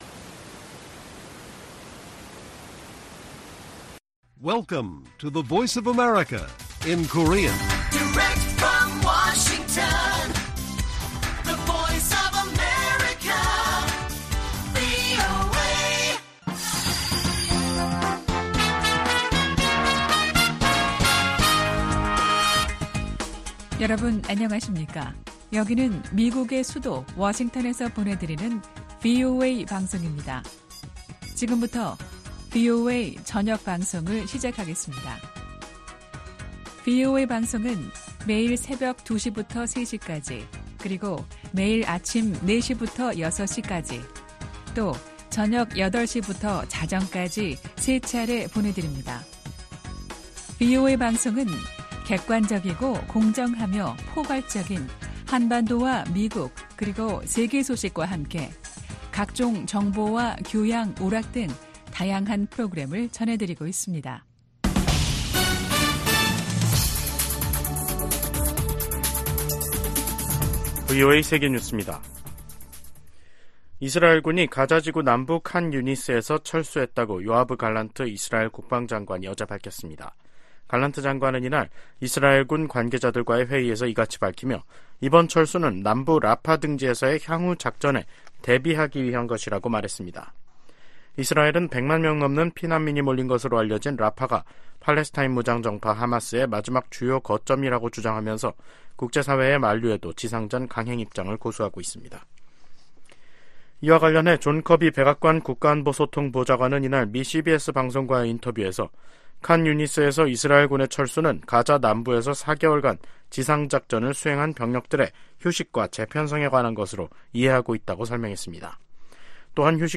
VOA 한국어 간판 뉴스 프로그램 '뉴스 투데이', 2024년 4월 8일 1부 방송입니다. 전천후 영상 정보 수집이 가능한 한국의 군사정찰위성 2호기가 8일 발사돼 궤도에 안착했습니다. 북한 김일성 주석을 ‘가짜’로 판단하는 1950년대 미국 정부 기밀 문건이 공개됐습니다. 미 국무부는 러시아가 한국의 대러 독자제재에 반발,주러 한국대사를 불러 항의한 것과 관련해 한국의 제재 조치를 환영한다는 입장을 밝혔습니다.